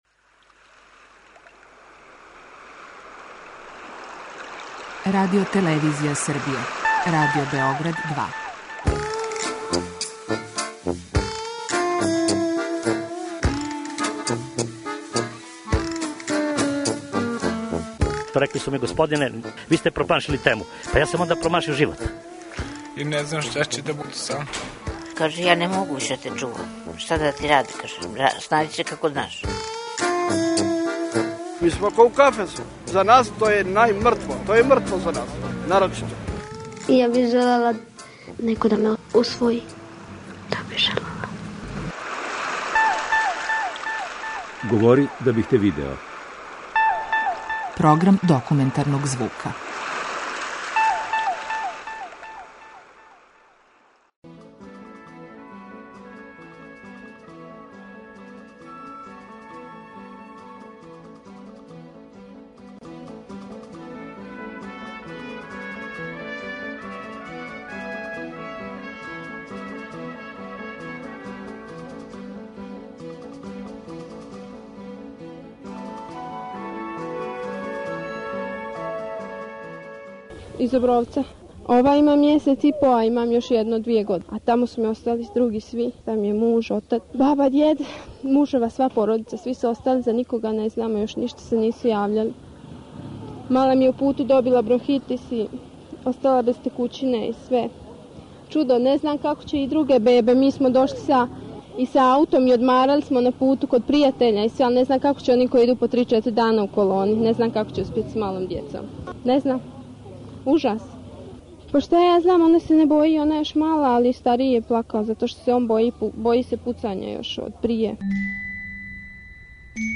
Документарни програм: 21 година од 'Олује'
Говоре људи који су протерани из Хрватске августа 1995. године.
преузми : 10.76 MB Говори да бих те видео Autor: Група аутора Серија полусатних документарних репортажа, за чији је скупни назив узета позната Сократова изрека: "Говори да бих те видео".